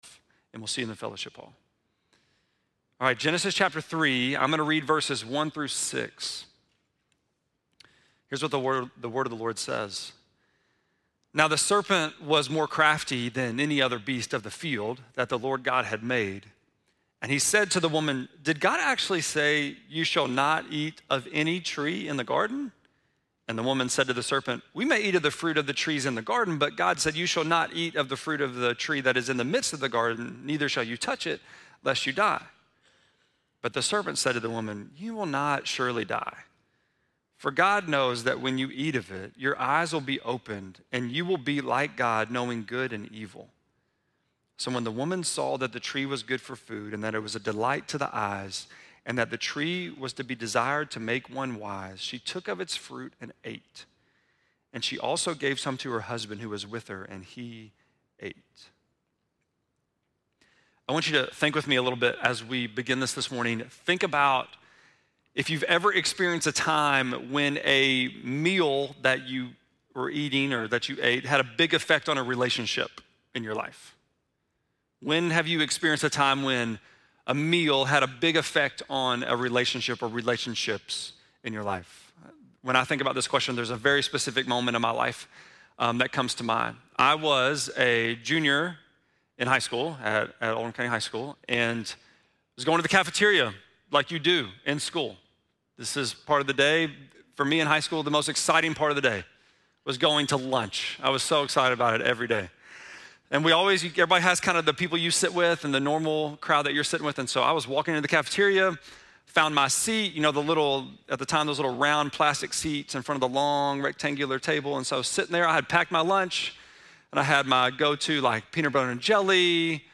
3.9-sermon.mp3